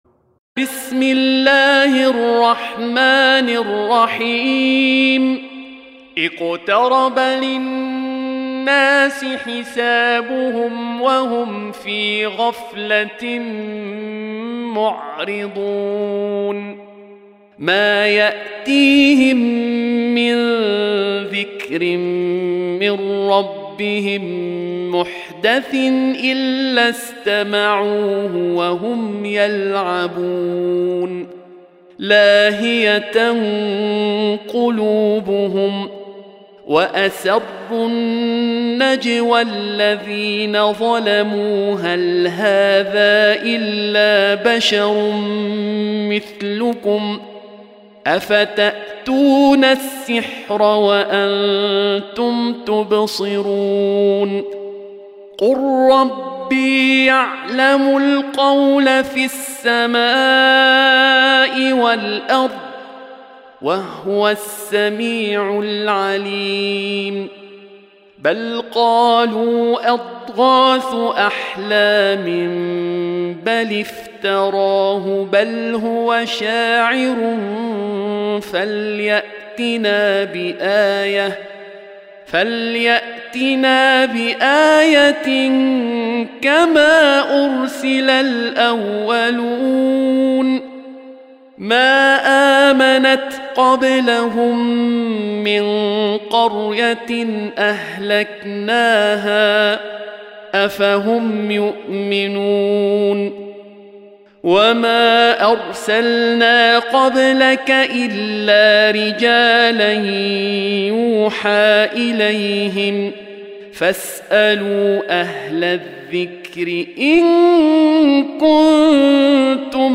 Surah Repeating تكرار السورة Download Surah حمّل السورة Reciting Murattalah Audio for 21.